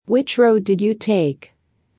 【ややスロー・スピード】